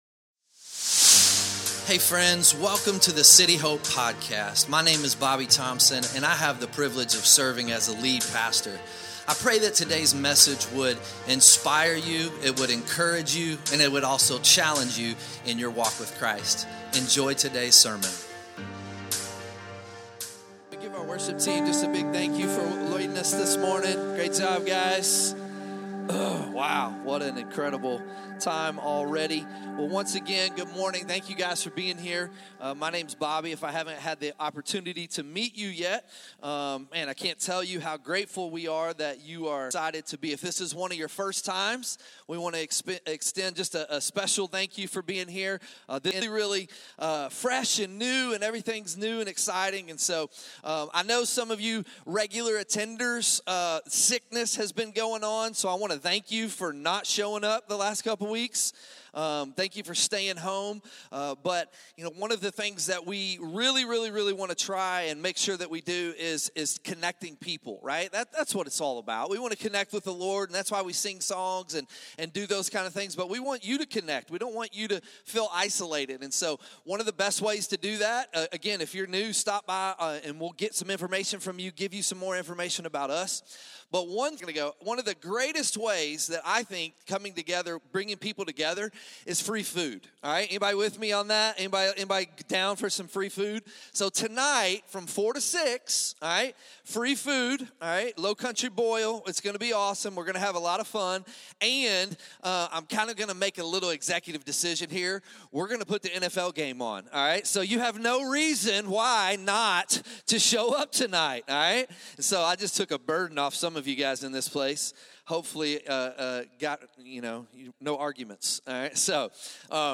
2026 Sunday Morning You don't grow best by just attending a church.